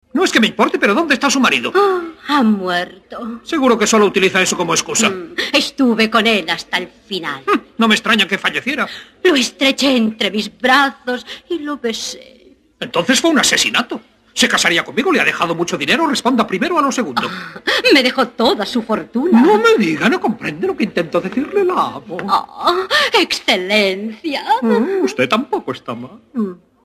Redoblaje aparte, los diálogos pueden seguirse correctamente aunque la música y sonidos de fondo pierden calidad sonora de forma considerable respecto a la versión original.
DD 2.0 mono Castellano